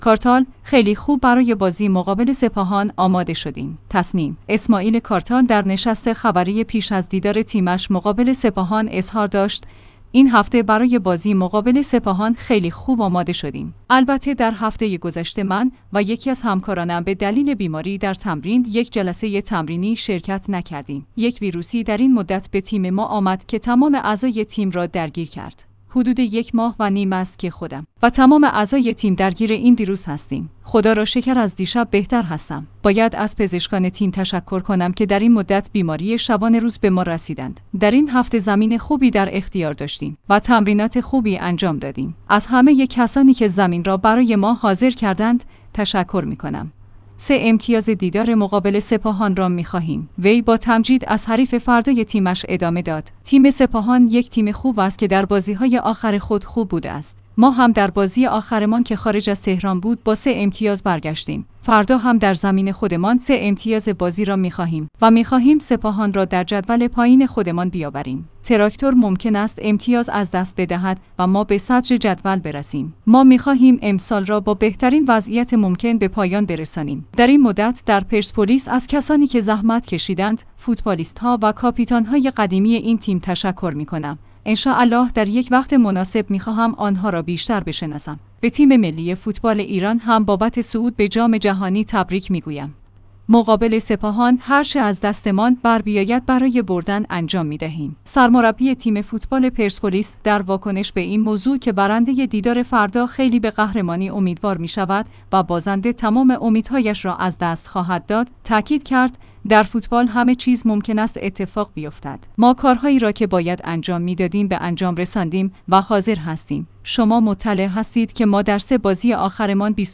تسنیم/اسماعیل کارتال در نشست خبری پیش از دیدار تیمش مقابل سپاهان اظهار داشت: این هفته برای بازی مقابل سپاهان خیلی خوب آماده شدیم.